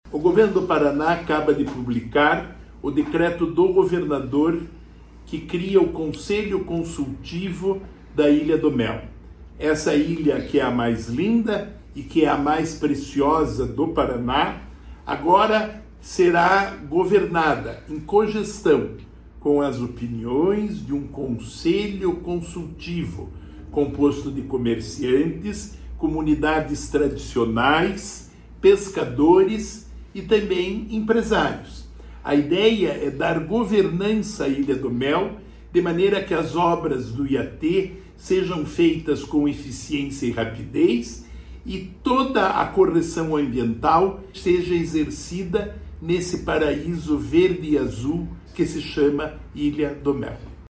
Sonora do secretário do Desenvolvimento Sustentável, Rafael Greca, sobre o Conselho Comunitário Consultivo da Ilha do Mel